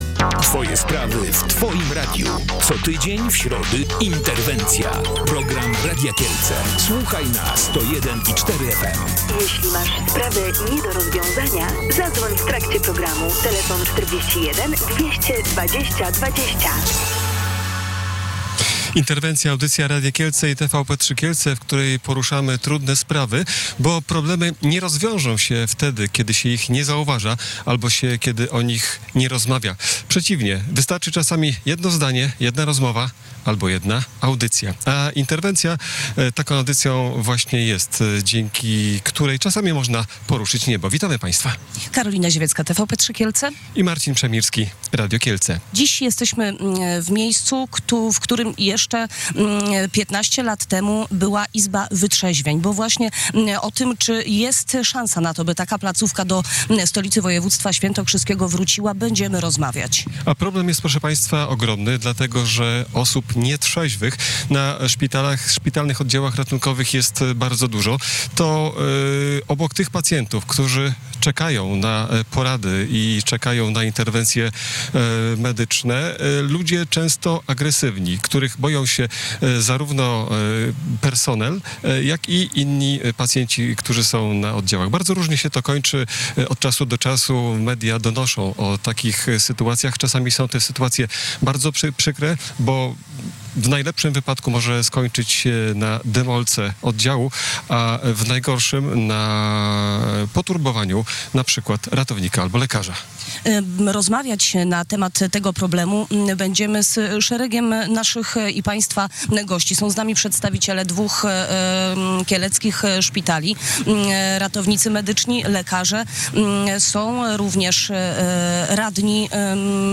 Program "Interwencja" Radia Kielce i TVP3 Kielce / Fot.